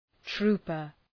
Προφορά
{‘tru:pər}
trooper.mp3